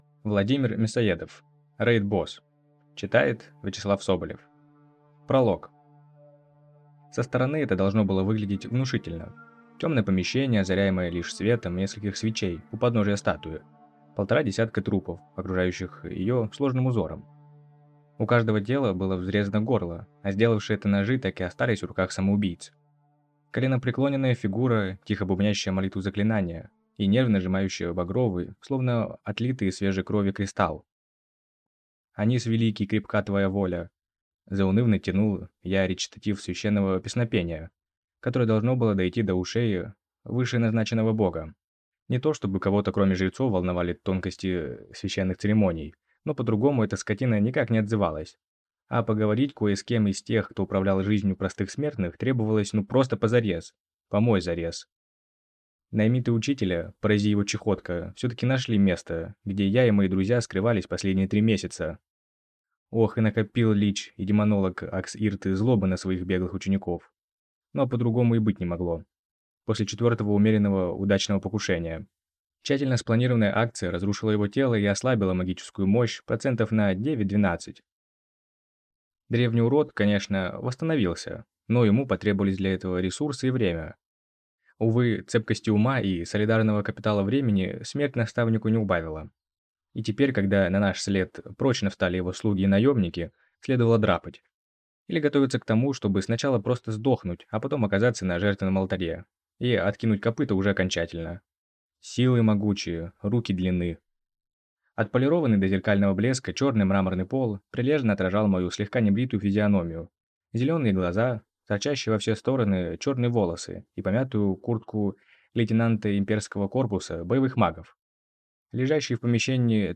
Аудиокнига Рейд-босс | Библиотека аудиокниг
Прослушать и бесплатно скачать фрагмент аудиокниги